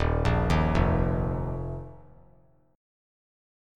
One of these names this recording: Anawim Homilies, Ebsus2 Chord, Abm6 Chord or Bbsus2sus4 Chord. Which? Ebsus2 Chord